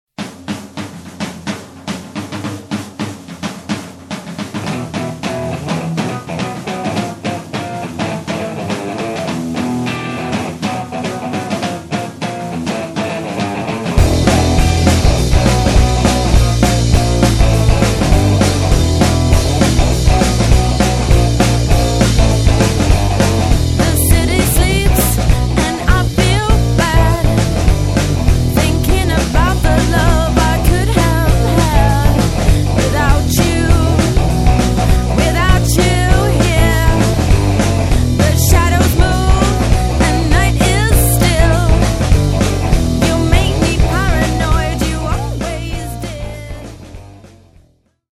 Recordings made at our studio facilities.